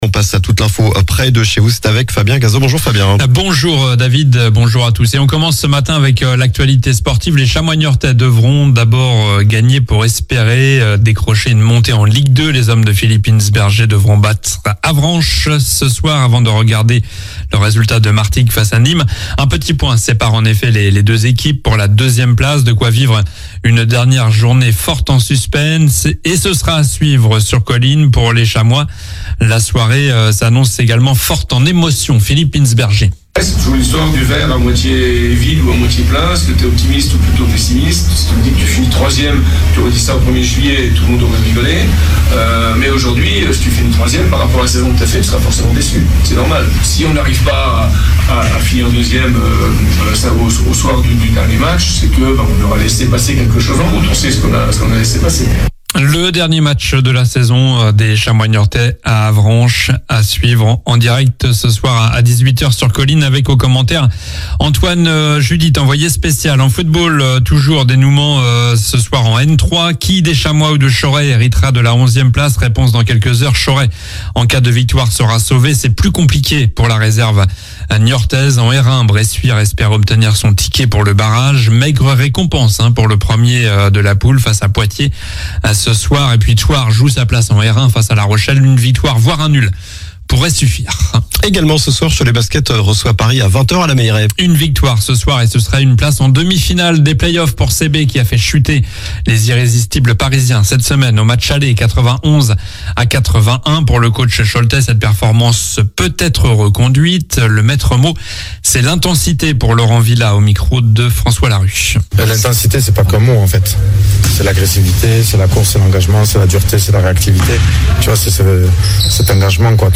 Journal du samedi 18 mai (matin)